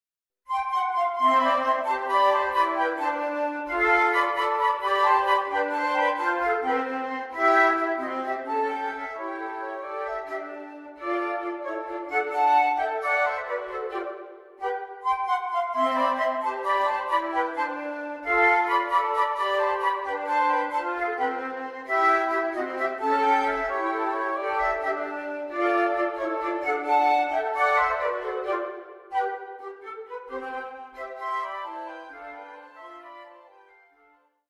Three easy, yet entertaining pieces for three flutes.